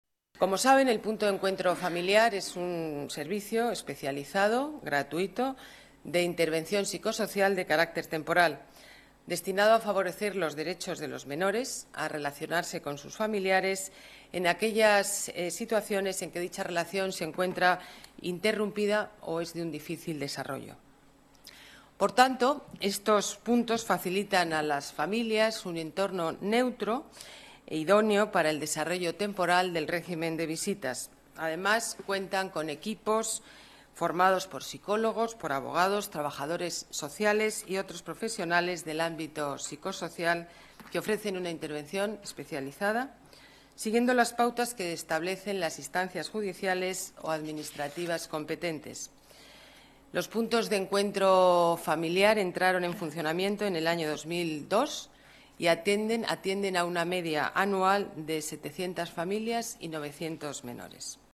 Nueva ventana:Declaraciones de Ana Botella: Puntos de Encuentro Familiar